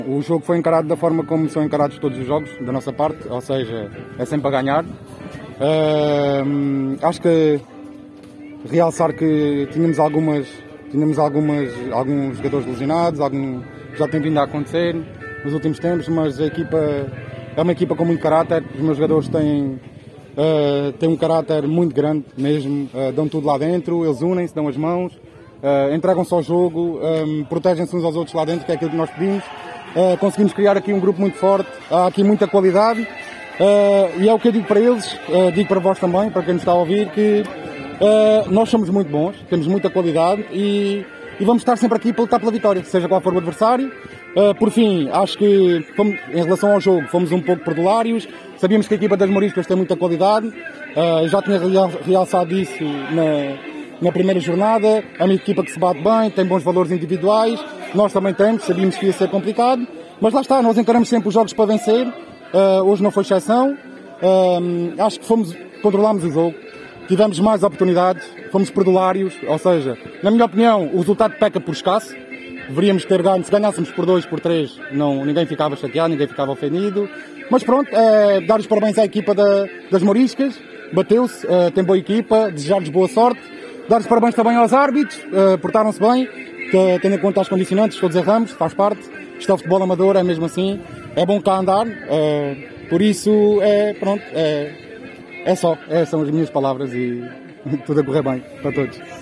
Como habitualmente fomos escutar os responsáveis técnicos de ambas as equipas: